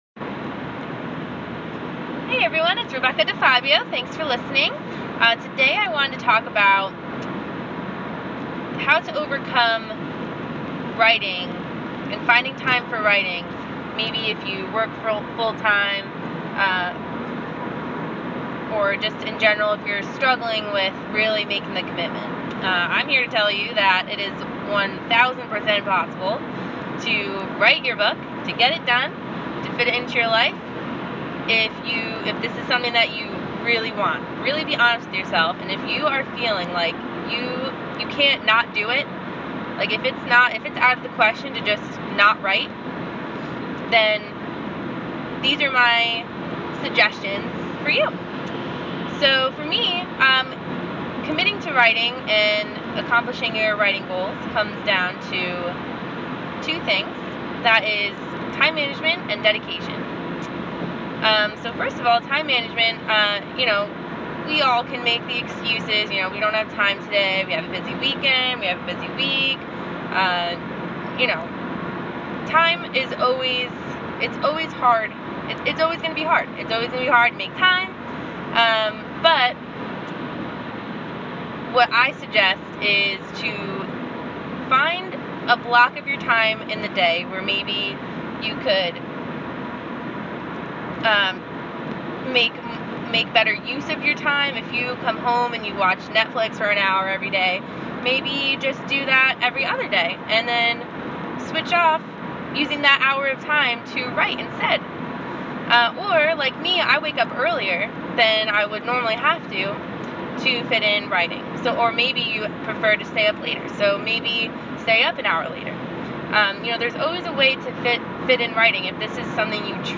Listen below for what was on my mind (and excuse the poor audio quality while I was driving–inspiration couldn’t wait!).